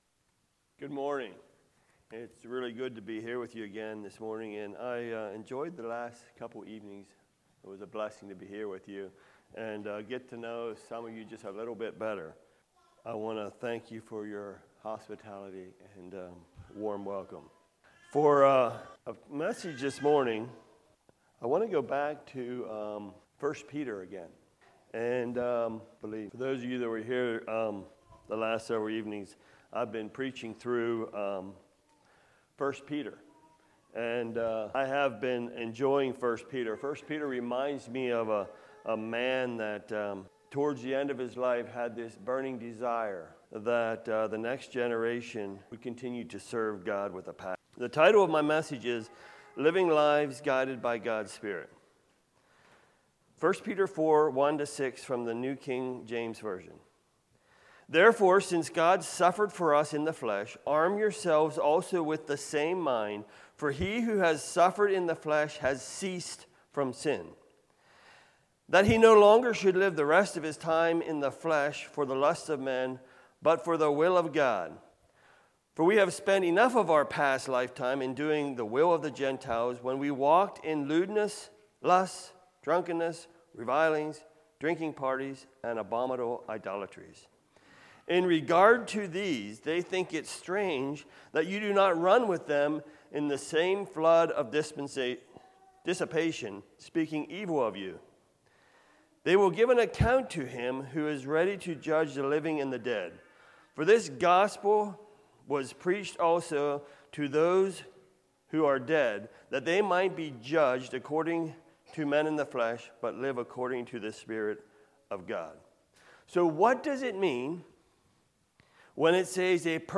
Passage: 1 Peter 4:1-6 Service Type: Revivals « What To Do Until The Lord Comes Back The Order of the Christian Family